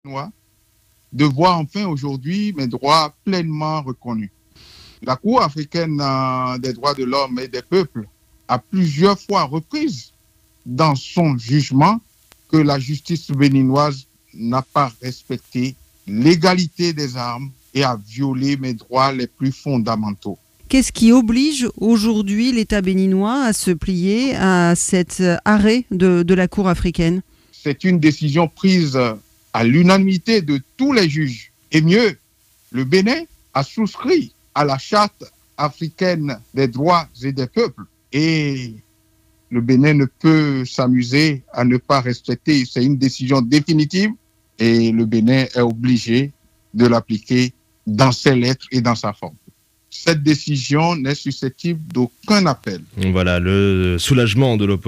C’est par ces mots, les larmes dans la voix, que l’ancien candidat à la présidence de la République et président du patronat béninois, Sébastien Ajavon, a commenté la décision prise à Arusha en fin d’après-midi.
La déclaration de Sébastien Ajavon à l’issue de l’audience de la CADHP